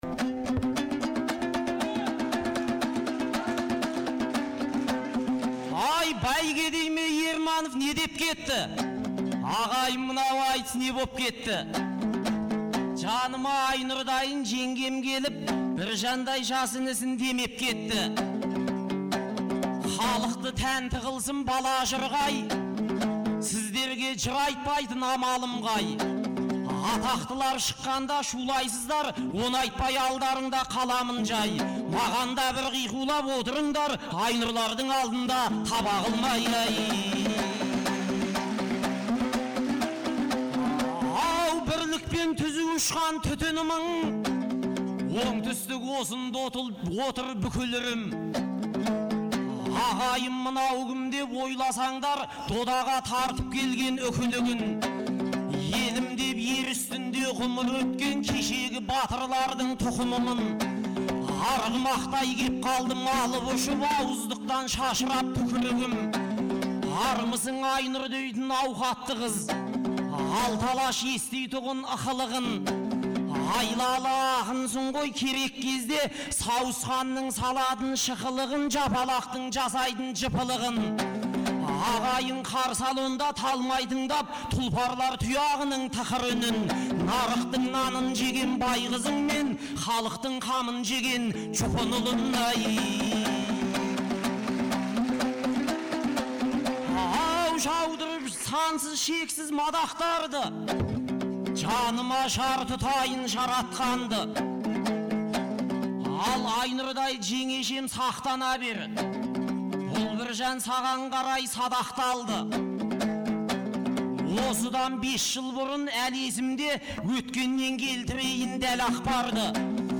Наурыздың 15-16-сы күндері Шымкент қаласында екі күнге созылған республикалық «Наурыз» айтысы өтті. 2004 жылдан бері тұрақты өтіп келе жатқан айтыс биыл Төле бидің 350 және Абылай ханның 300 жылдықтарына арналды.